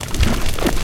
PixelPerfectionCE/assets/minecraft/sounds/mob/magmacube/jump3.ogg at mc116
jump3.ogg